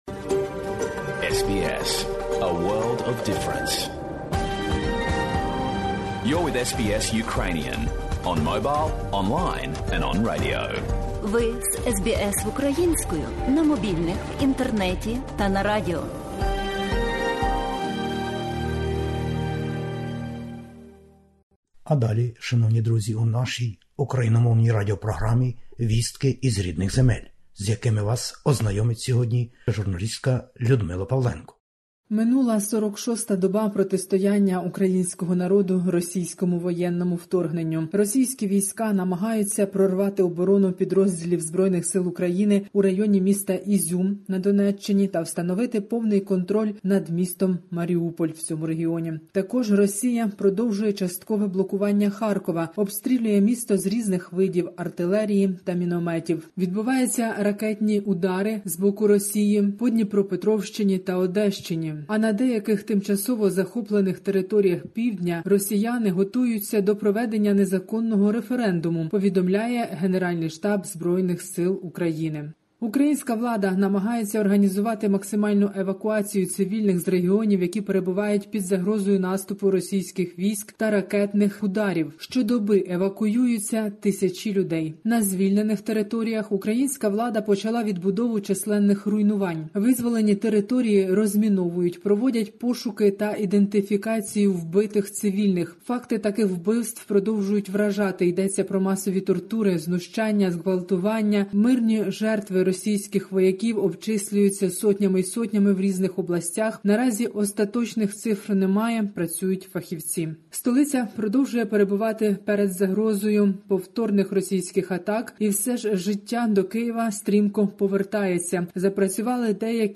Добірка новин із воюючої України.